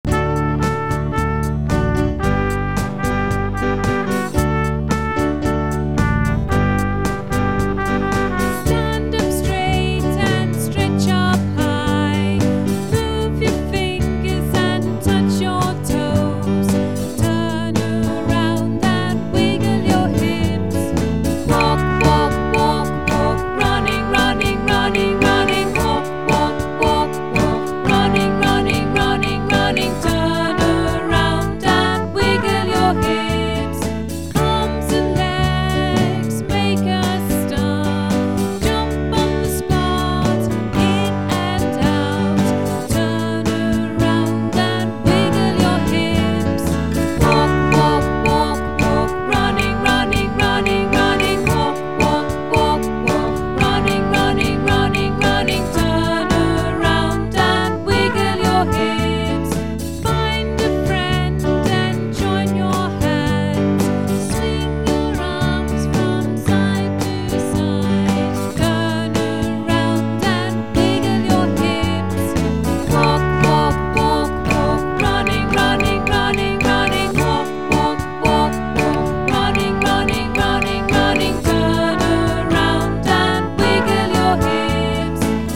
recorded with the teachers and children of Kelsey Yaralla Kindergarten in Dunedin.